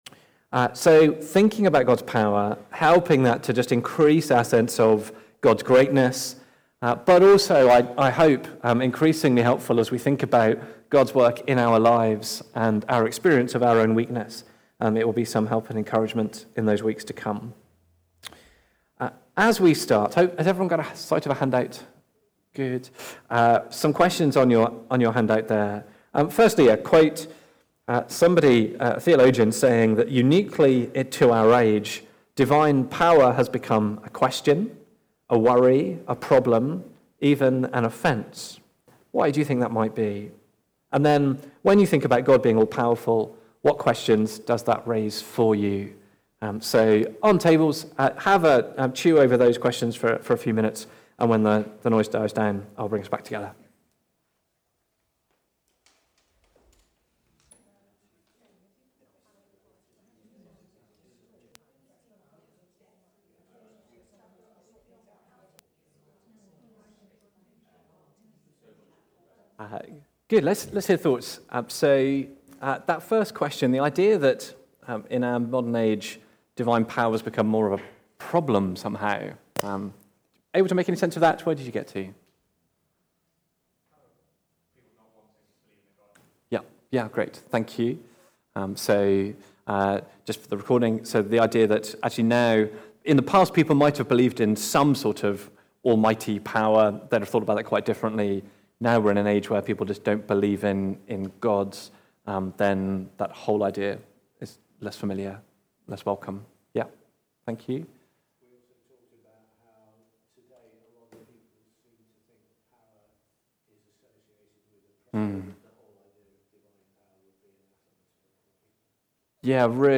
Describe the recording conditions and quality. God's Power in Creation (Psalm 65) from the series The Power Of God. Recorded at Woodstock Road Baptist Church on 08 March 2026.